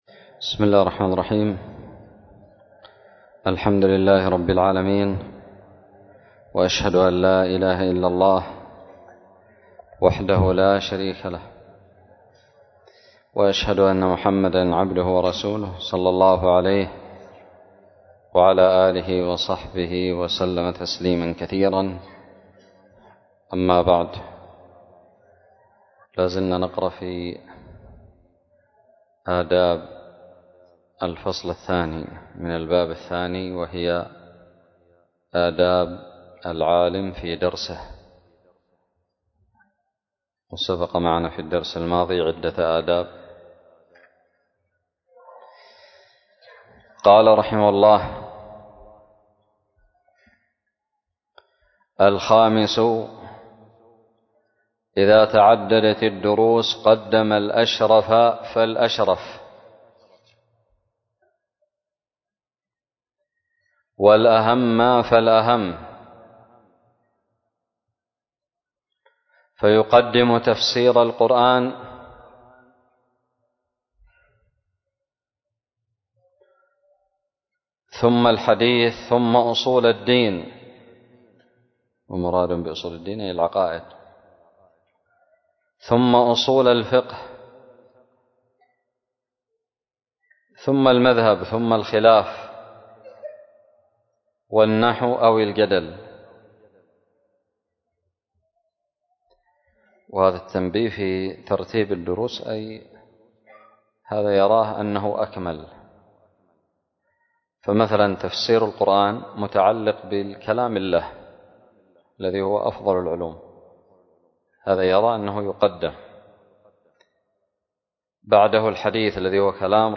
الدرس الثالث عشر من شرح كتاب تذكرة السامع والمتكلم 1444هـ
ألقيت بدار الحديث السلفية للعلوم الشرعية بالضالع